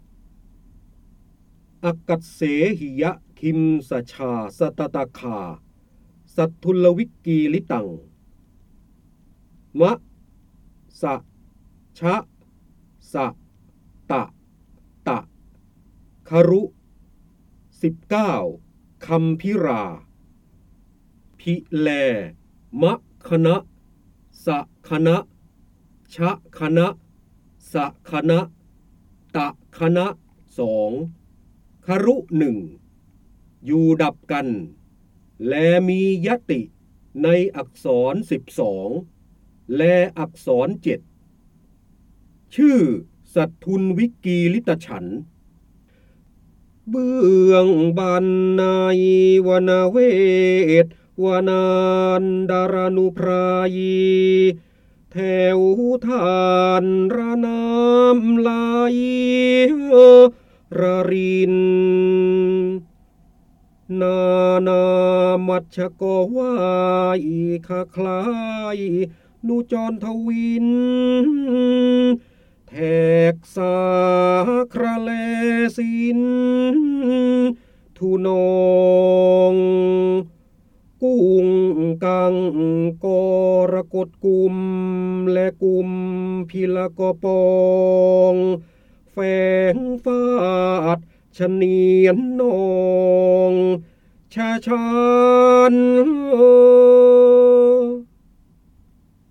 เสียงบรรยายจากหนังสือ จินดามณี (พระโหราธิบดี) อักกัส์เสหิยทิม์สชาสตตคา
คำสำคัญ : พระโหราธิบดี, ร้อยแก้ว, การอ่านออกเสียง, ร้อยกรอง, จินดามณี, พระเจ้าบรมโกศ